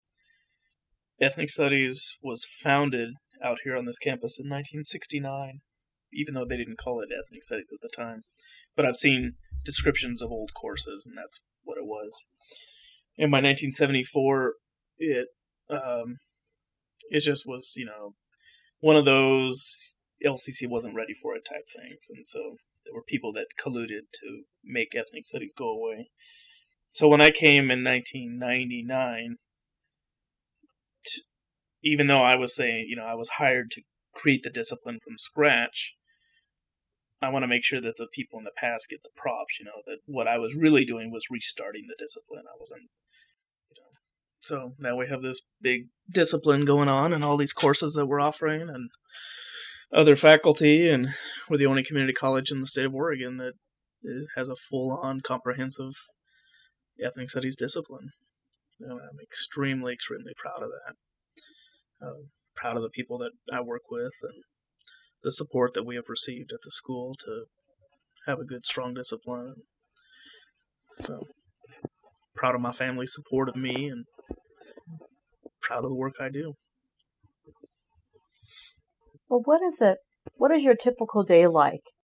Oral History
Here are excerpts from the interview